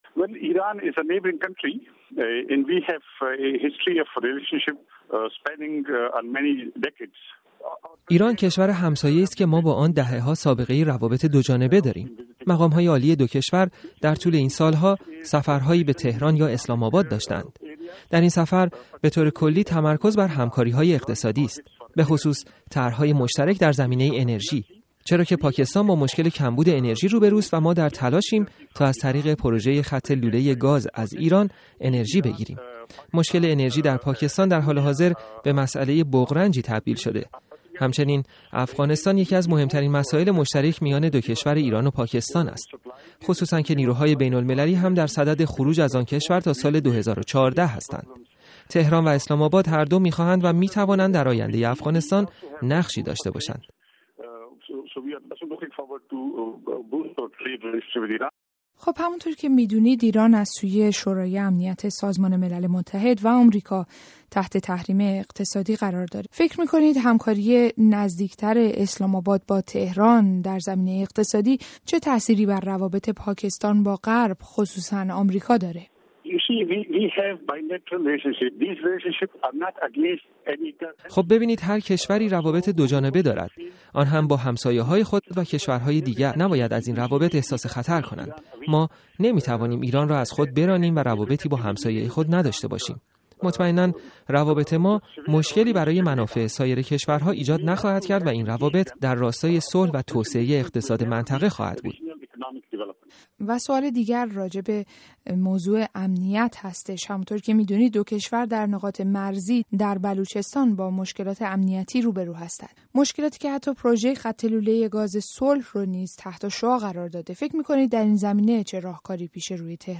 گفت‌گوی
افراسیاب ختک، سناتور در مجلس سنای پاکستان